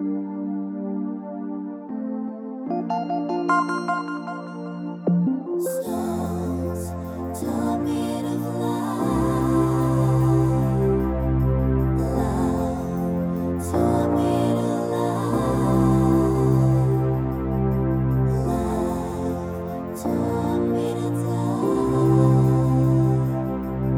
Pop (2010s)